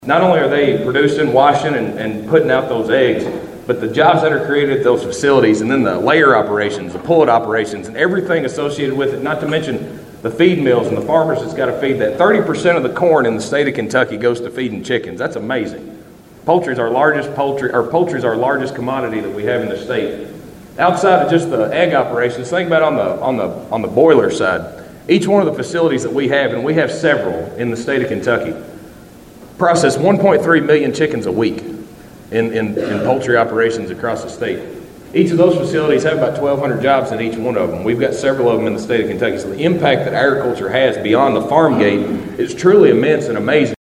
Shell was the featured speaker for the Todd County Agriculture Appreciation Breakfast at the Elkton Baptist Church Fellowship Hall Friday morning.